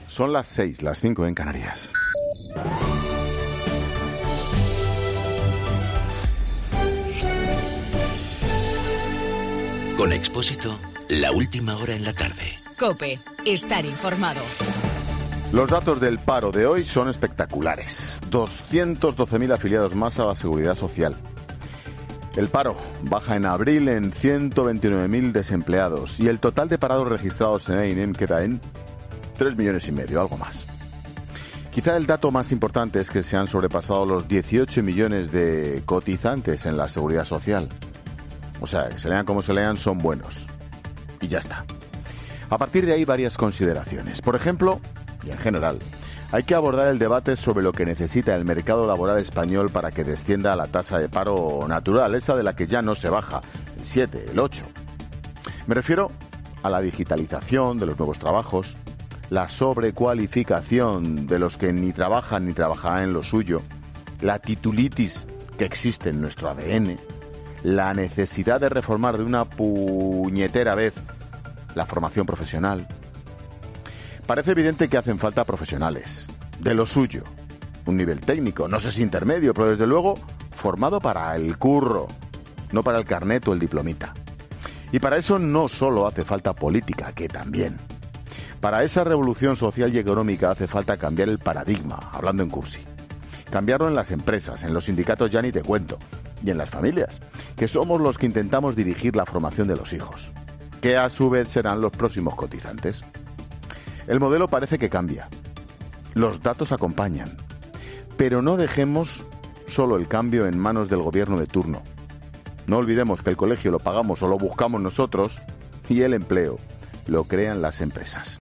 AUDIO: Monólogo 18h.